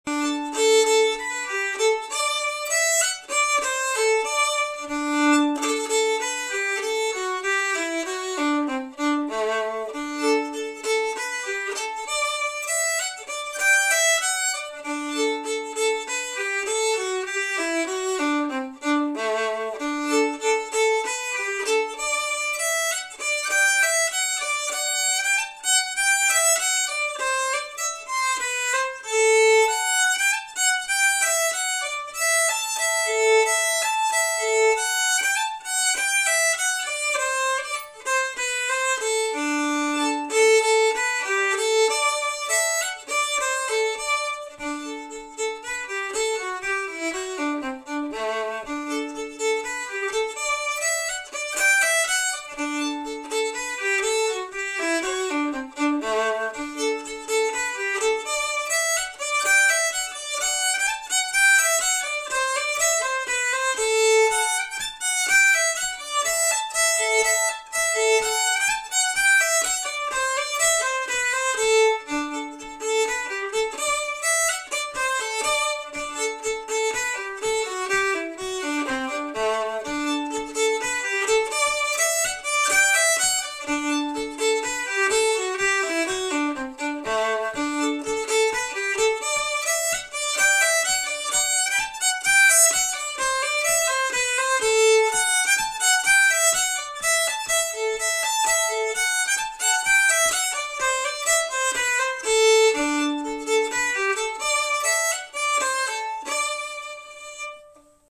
Key: D dorian, mixolydian?
Form: Jig?
Played slowly, then twice up-to-tempo